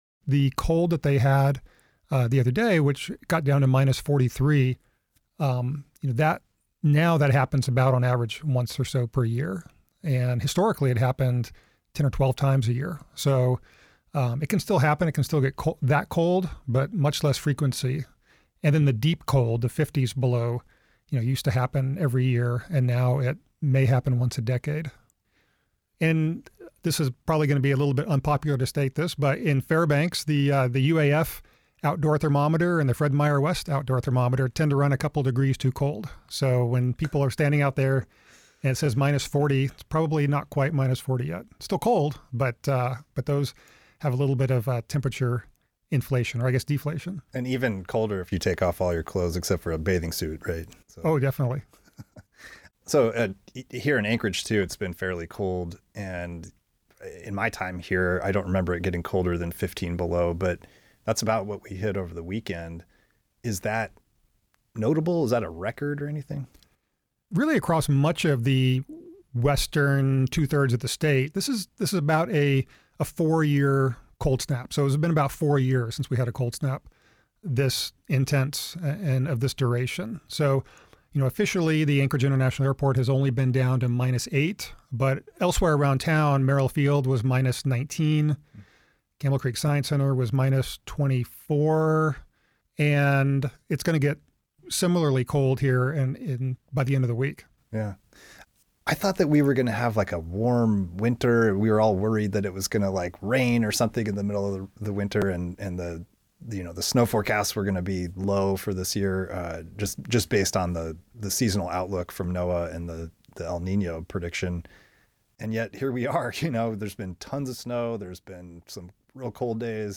This interview has been lightly edited for length and clarity.